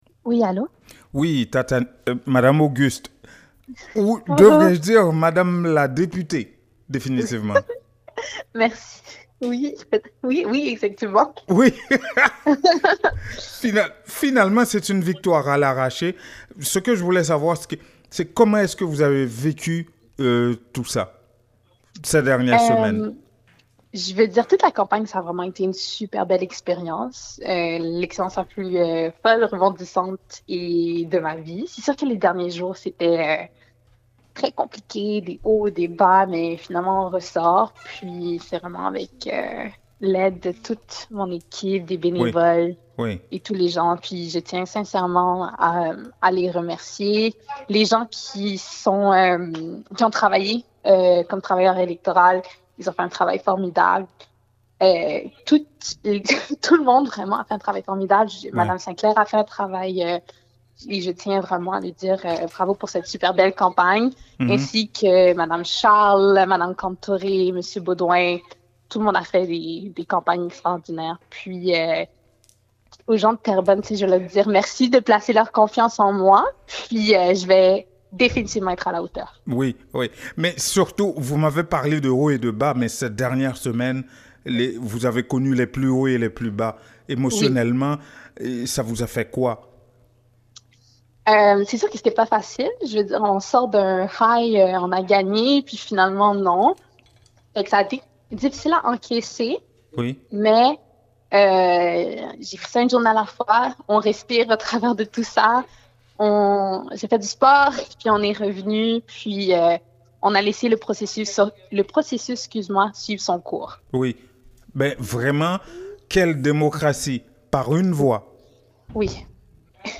Écouter les déclarations de Tatiana Auguste après son élection comme députée de Terrebonne, au Québec.